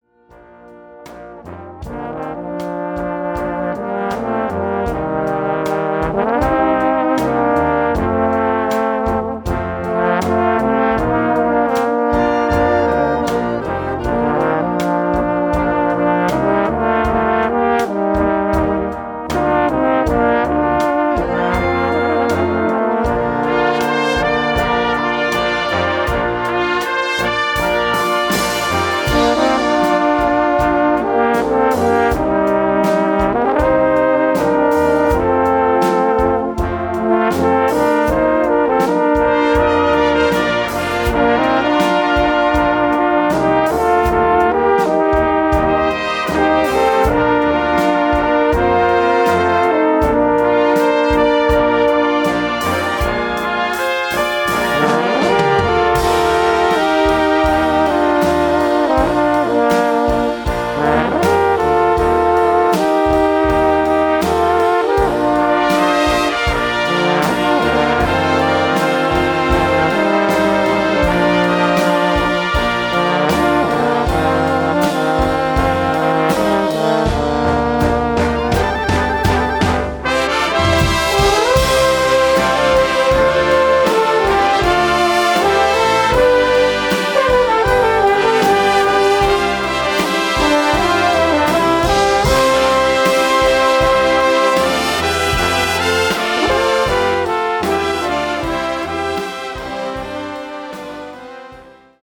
Gattung: Solo für zwei Tenorhörner und Blasorchester
Besetzung: Blasorchester